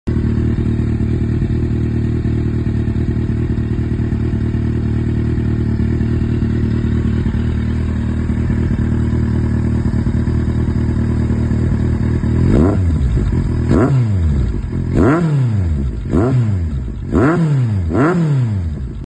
✅ Sonido más agresivo ✅ Petardeo controlado
✅ Deeper, more aggressive tone ✅ Clean crackle